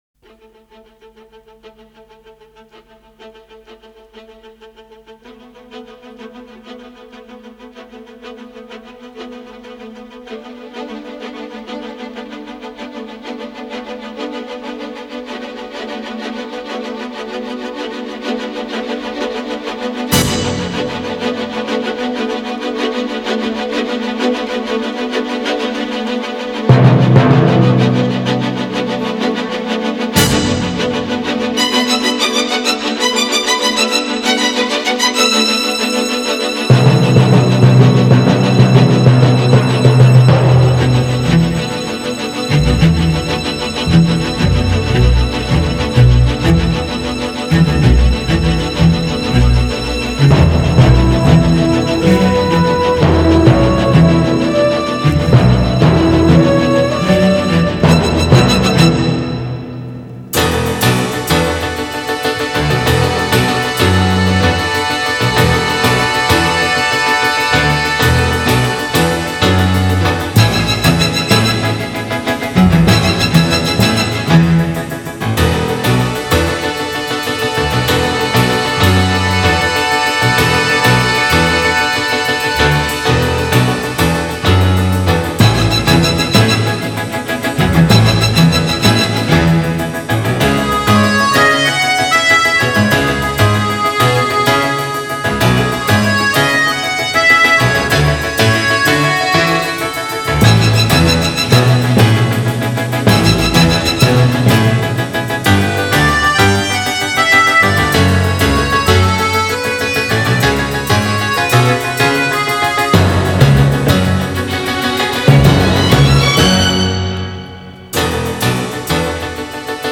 장르: Electronic, Jazz, Pop
스타일: Modern Classical, Easy Listening